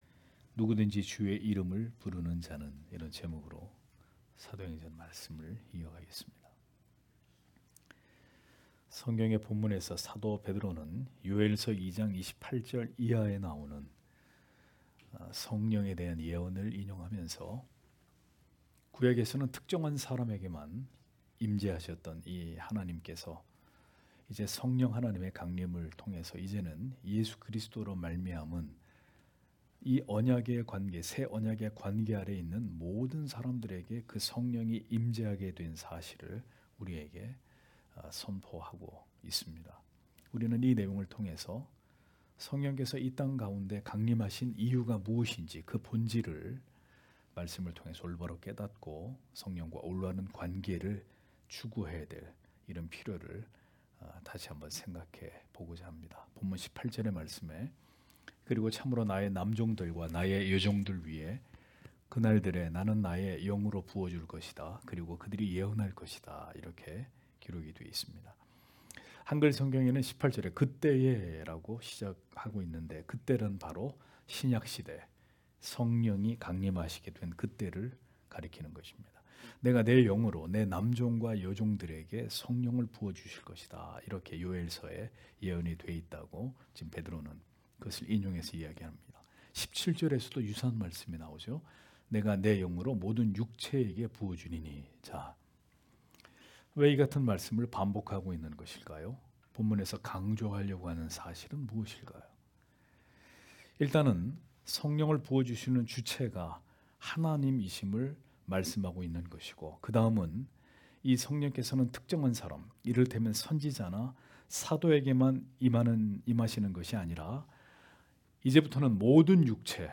금요기도회 - [사도행전 강해 12]누구든지 주의 이름을 부르는 자는 (행 2장 18- 21절)